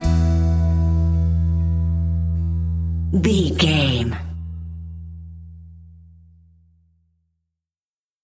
Ionian/Major
drums
acoustic guitar
piano
violin
electric guitar